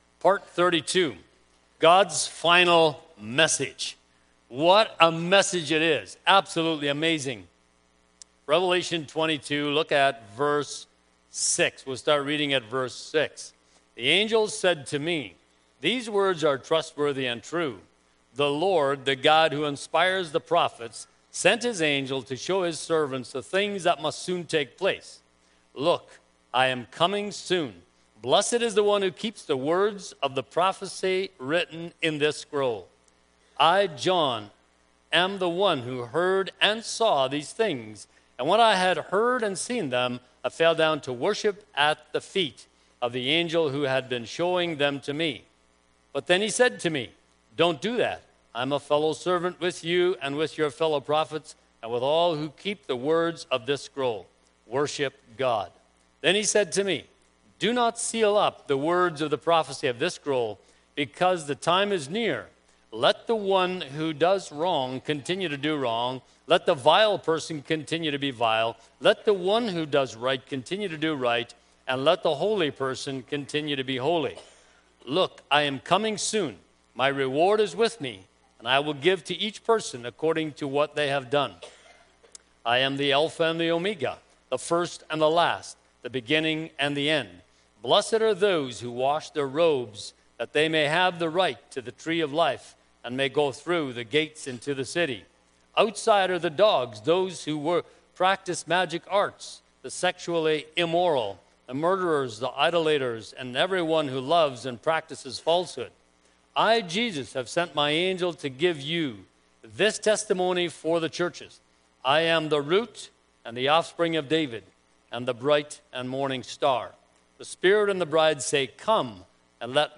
The Book of Revelation Service Type: Sunday Morning Preacher